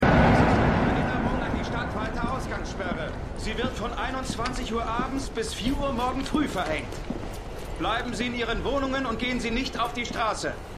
Synchronstudio: Berliner Synchron GmbH